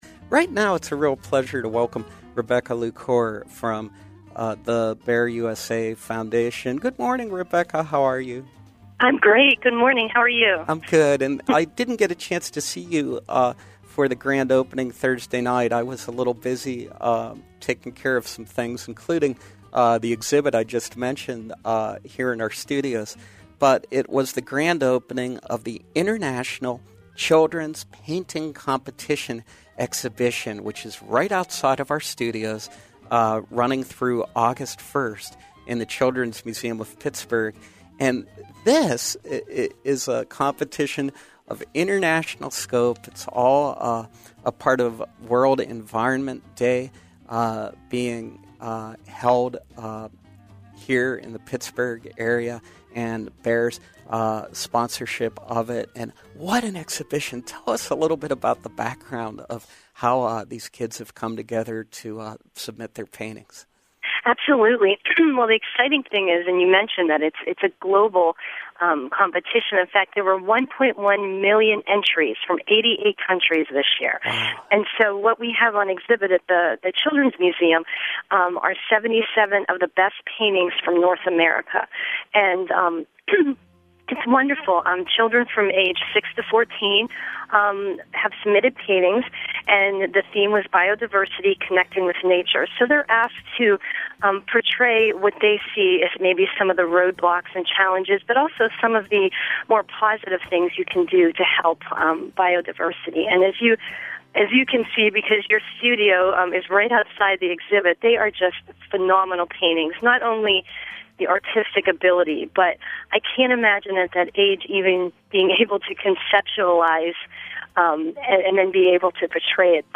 Home » Conversation, Interviews